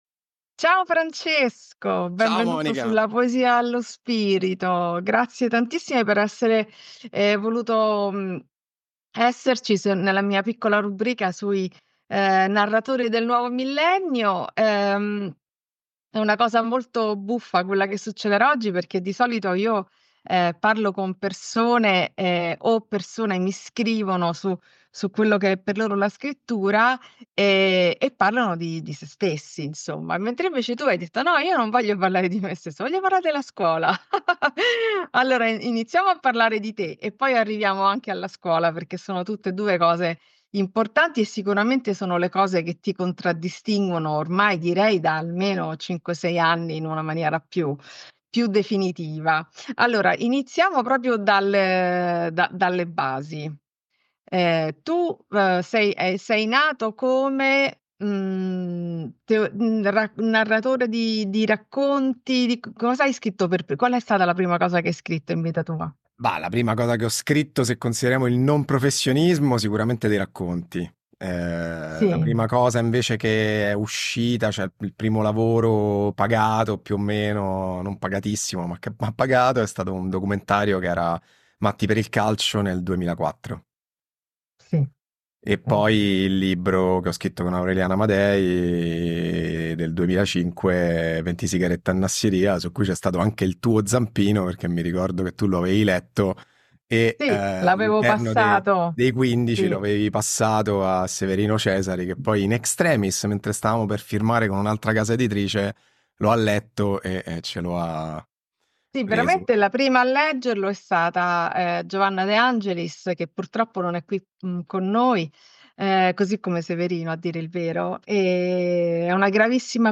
Videointervista